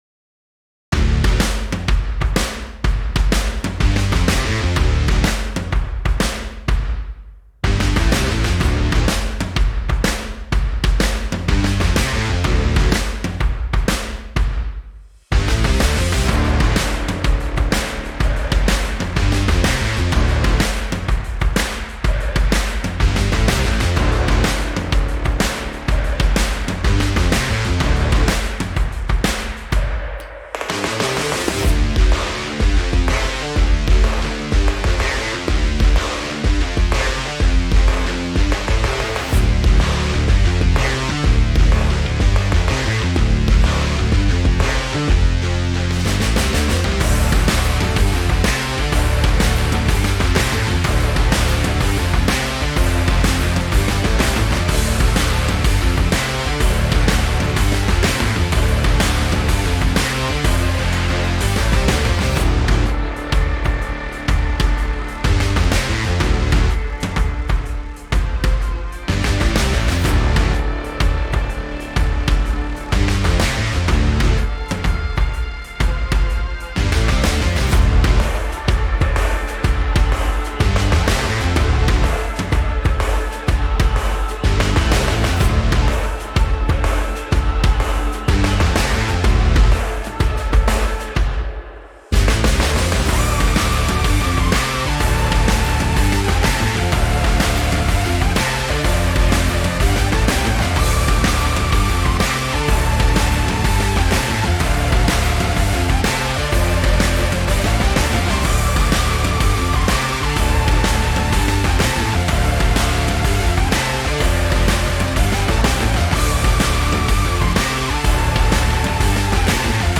Rock-gym-music.wav